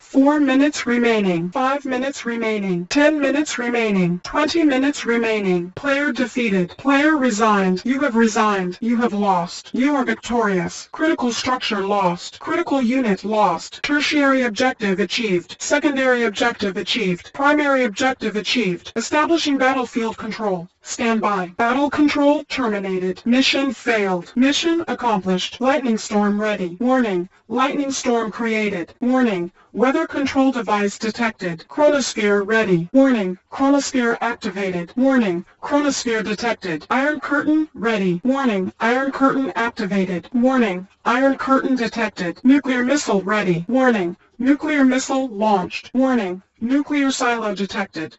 Yuri EVA female voices [WAV]
Just some Allied EVA voices morphed/edited.
I actually think it's pretty decent, though it could do with some cleaning up, easier said than done, I know.
eh not bad..its got this slurred monotone like vibe...which can fit a mc lady...but lacks personality..even if its mc'd maybe choking,drooling,gaggin...some character.